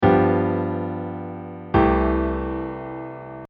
Så en variant som vi ofte finner i jazzvoicinger, nemlig at vi lander på en sekstakkord.
Dominantseptim til Tonikasekstakkord (G7-C6)
G7 – C6 – lydeksempel